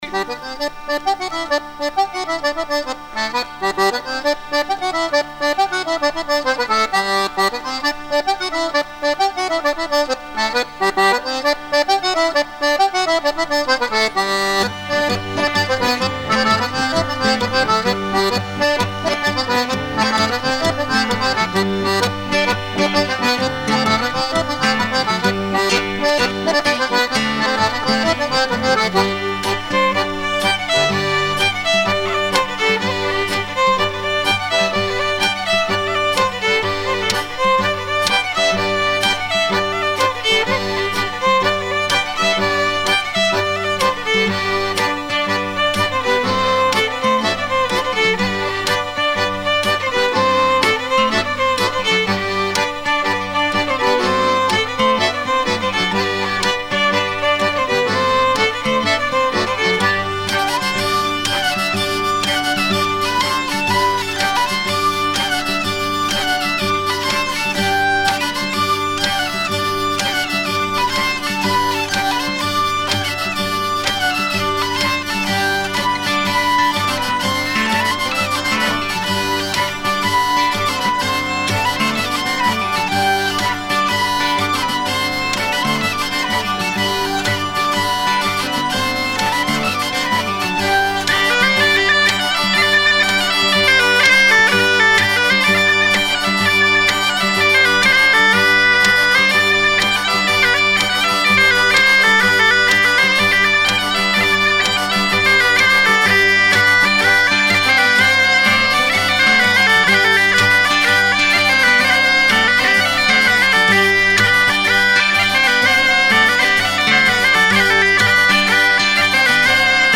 à virer au cabestan
Chants de marins